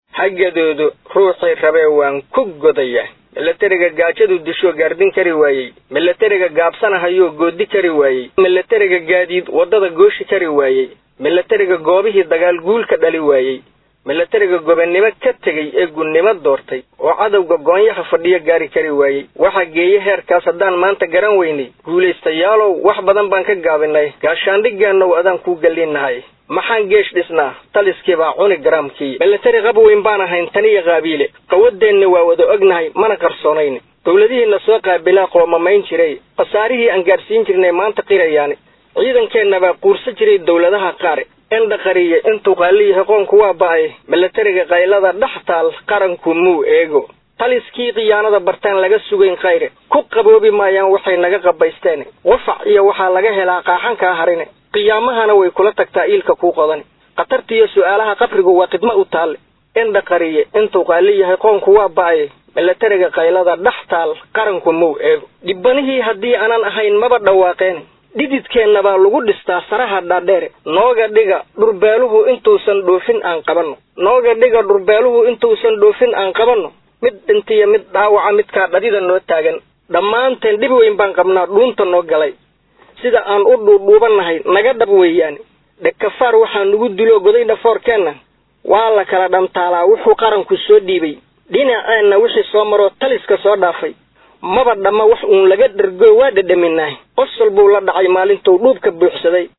Gabay Dhaliila